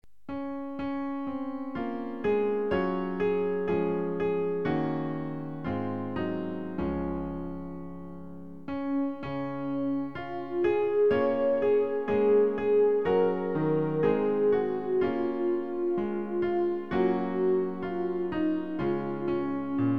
Klavier-Playback zur Begleitung der Gemeinde